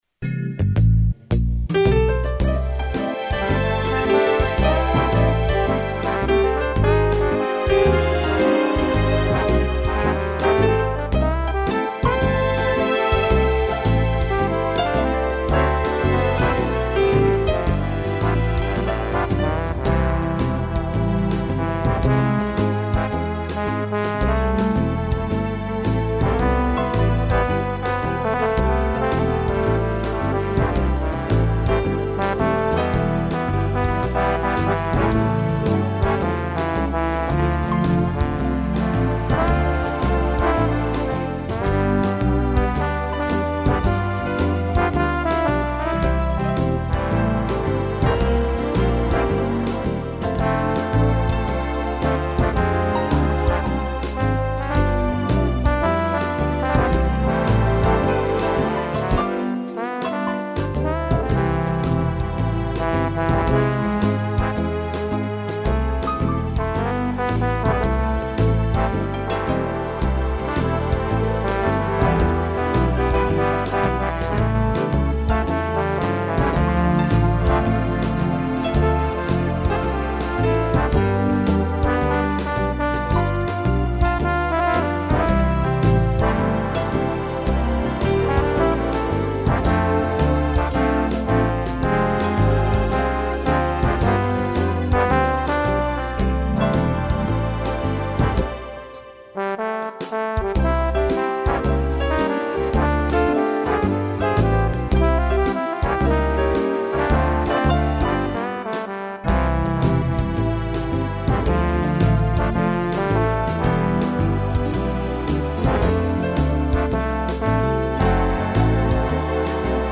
piano e trombone (instrumental)